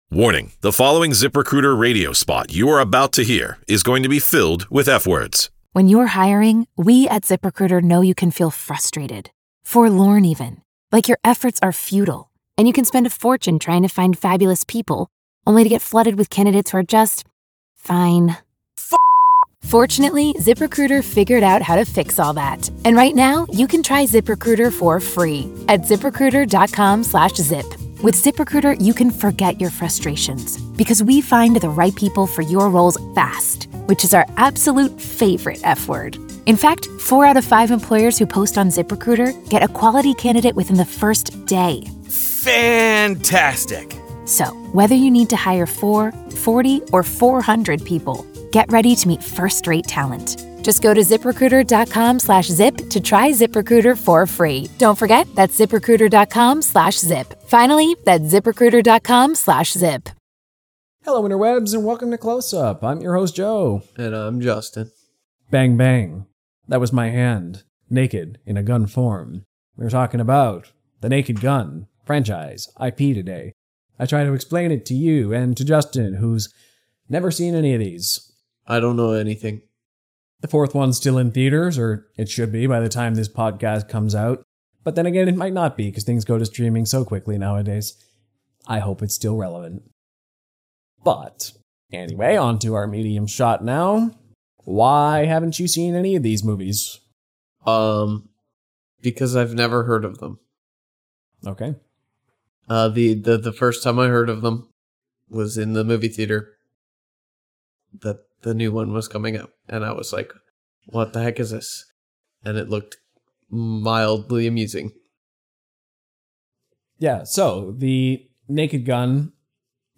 Film, TV, and comic book discussions abound! So does laughter, passionate debate, and thoughtful discussion.